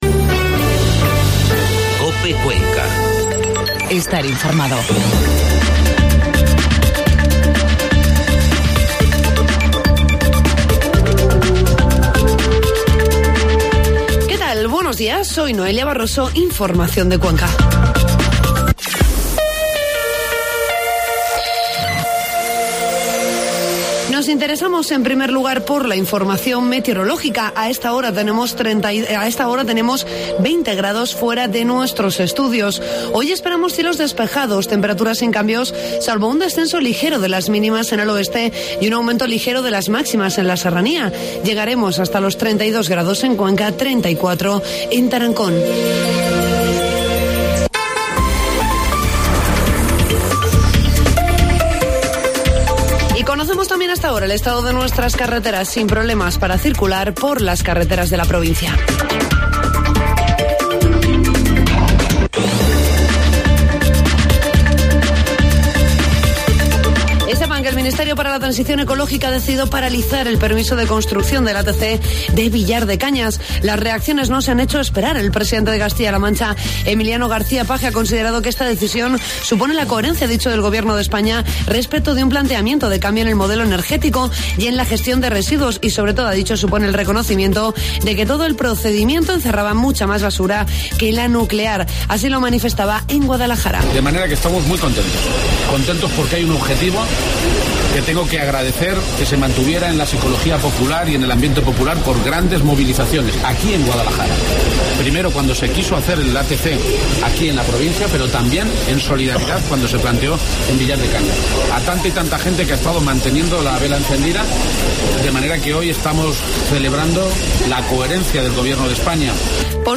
Redacción digital Madrid - Publicado el 19 jul 2018, 11:24 - Actualizado 15 mar 2023, 12:45 1 min lectura Descargar Facebook Twitter Whatsapp Telegram Enviar por email Copiar enlace Informativo matinal 19 de julio